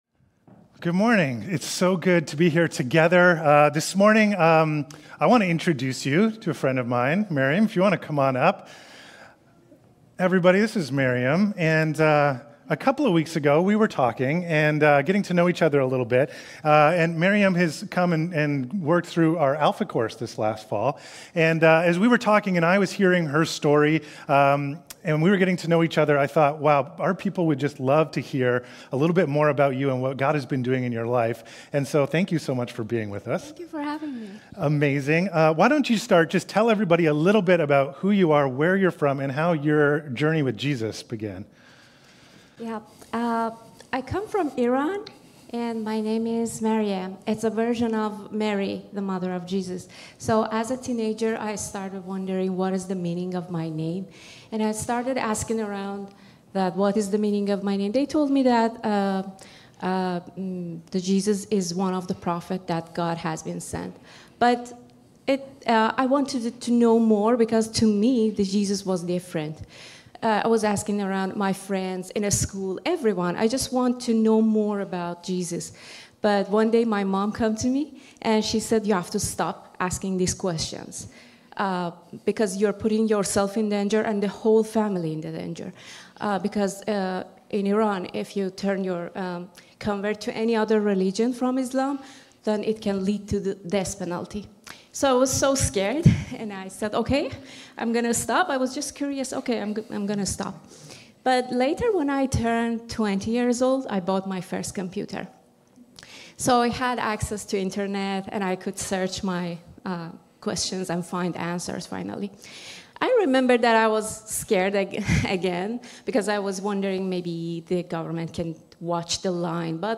Sermons | Westside Church